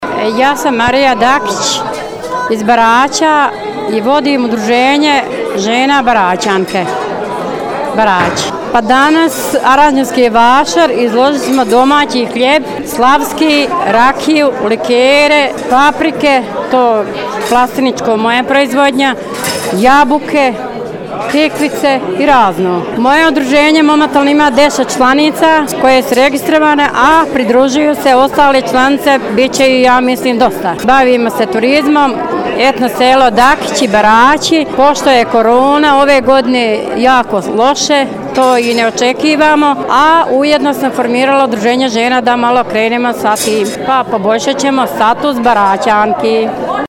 izjavu